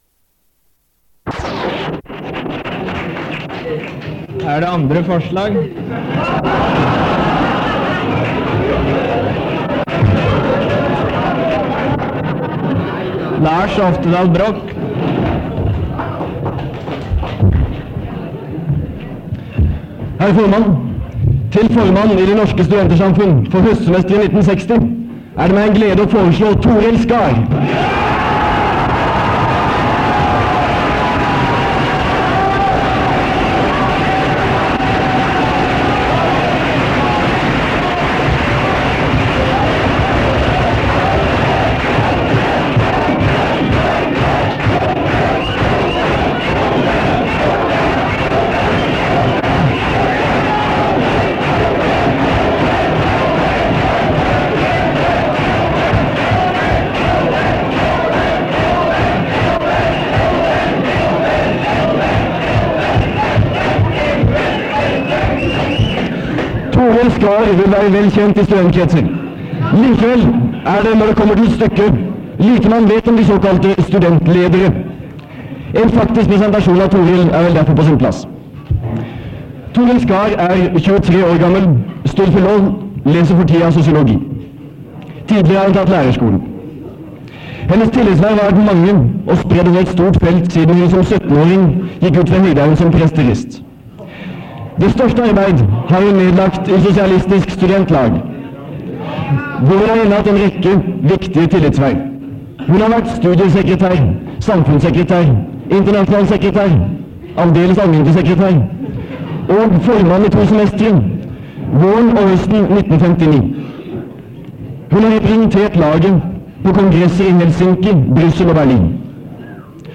Noe dårlig lyd